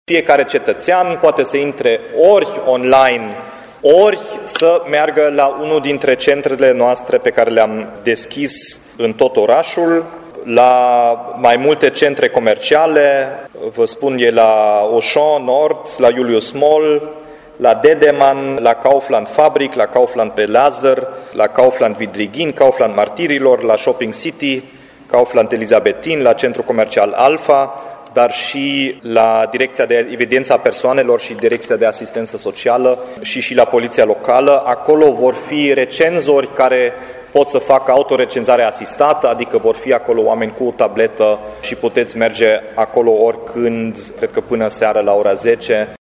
Birourile funcționează în centre comerciale dar și la diverse instituții , anunță primarul Dominic Fritz.
Primarul Dominic Fritz a transmis că participarea la recensământ a timișorenilor este foarte importantă, pentru că în funcție de asta, Timișoara va primi bani de la bugetul de stat în viitor.